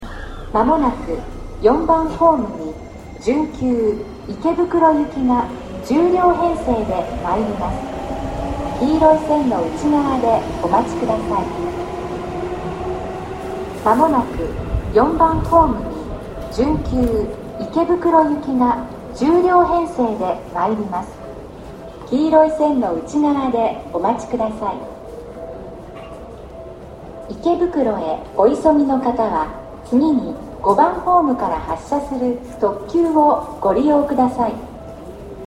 ４番ホームSI：西武池袋線
接近放送準急　池袋行き接近放送です。